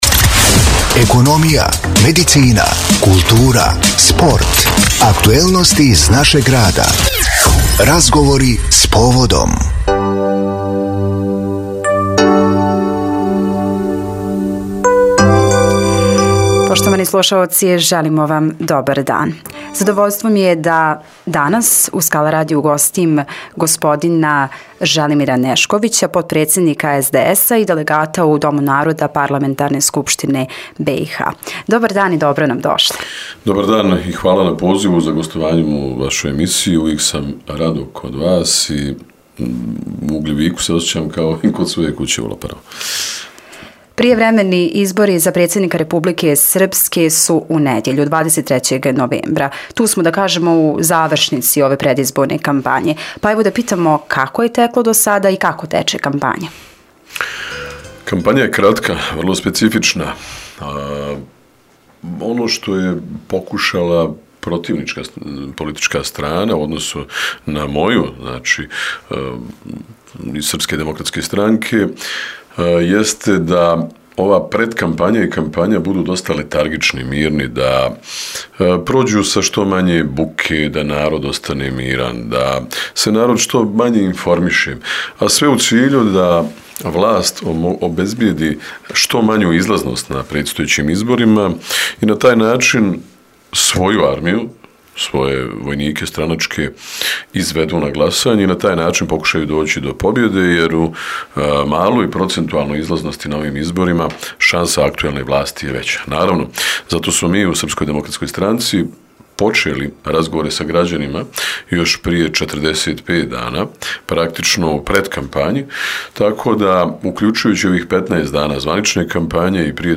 GOST U STUDIJU SKALA RADIJA: DR ŽELIMIR NEŠKOVIĆ – POTPREDSJEDNIK SDS- A I DELAGAT U DOMU NARODA PARLAMENTARNE SKUPŠTINE BIH
U okviru predizborne kampanje gost u Skala radiju bio je potpredsjednik SDS- a i Delagat u Domu naroda Parlamentarne skupštine BIH, dr Želimir Nešković.